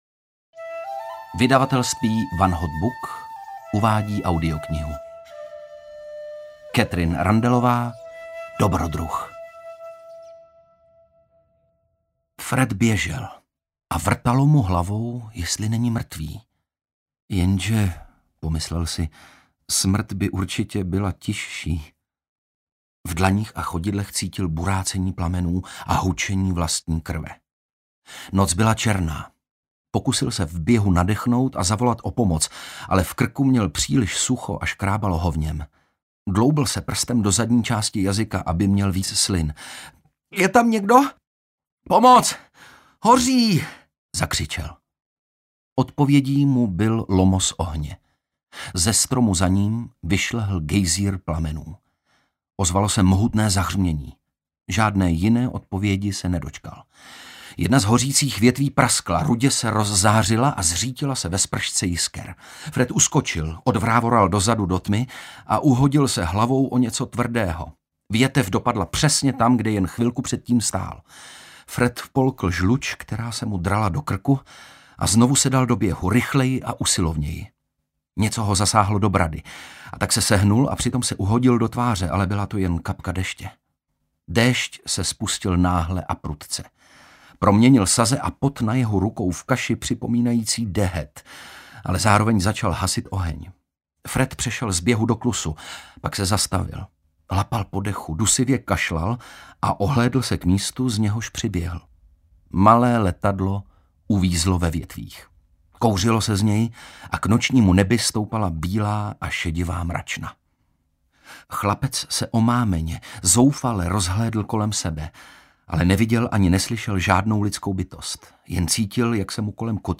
Dobrodruh audiokniha
Ukázka z knihy
• InterpretOndřej Brousek